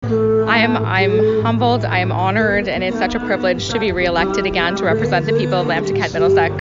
When speaking to the press after her speech, Rood said that overall, she’s honored to be re-elected.